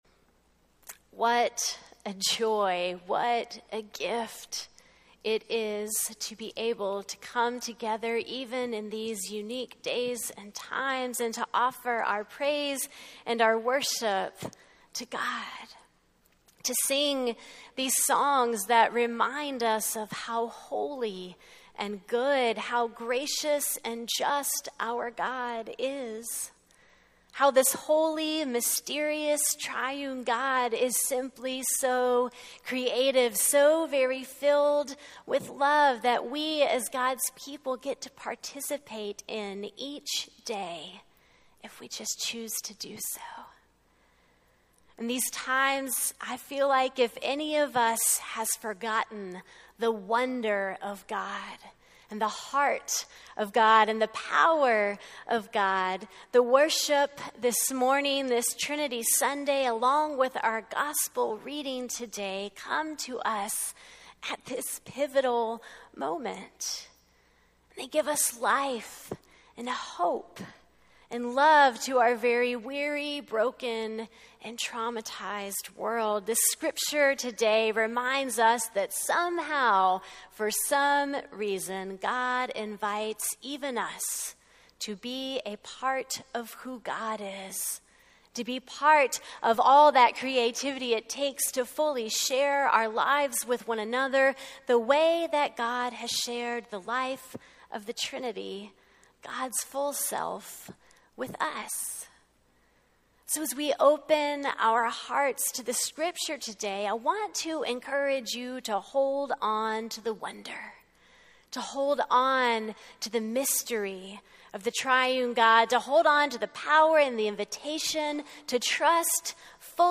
A message from the series "The Gospel of Matthew."
6-7-service.mp3